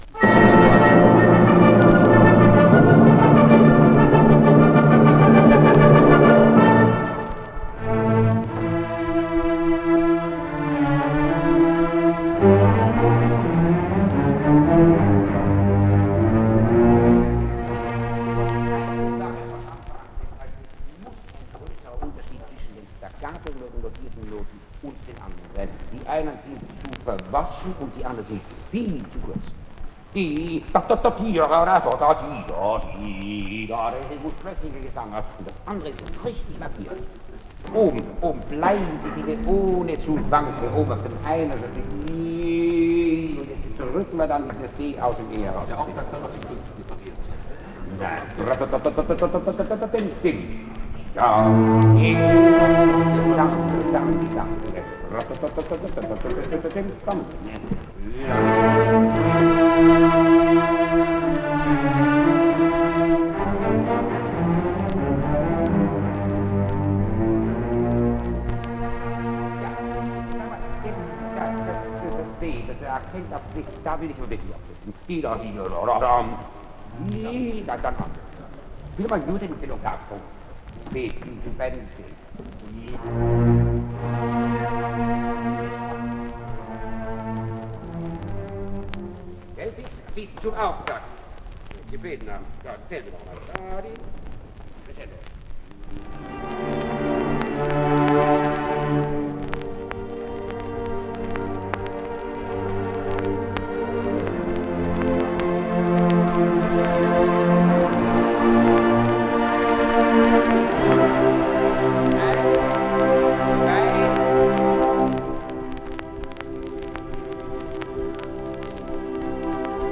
Караян репетирует Бетховена...
Идёт репетиция Берлинского симфонического оркестра, с которым Герберт фон Караян работает над Девятой симфонией.
Вот знаменитая интродукция-речитатив - инструментальное преддверие финального хора.
Звуковая страница 8 - Герберт Караян на репетиции Девятой симфонии Бетховена.